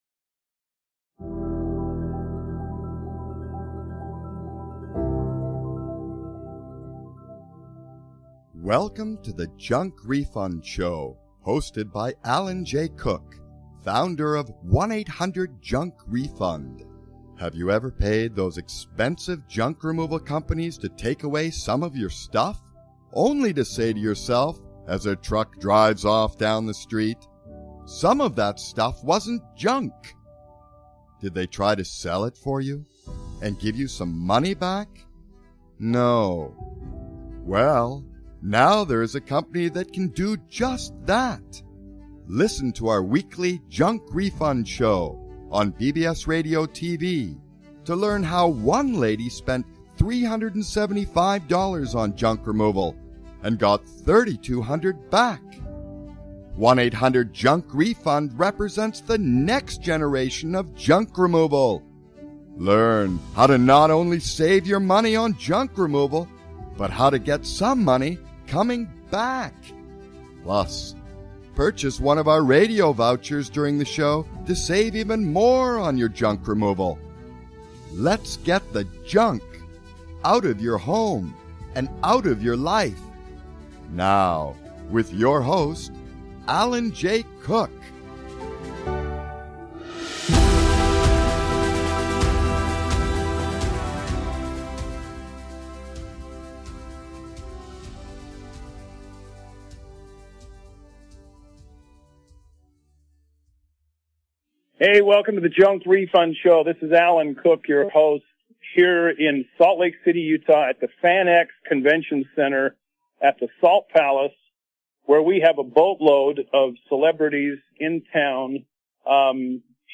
Talk Show Episode, Audio Podcast, Junk Refund Show and Dr. Who?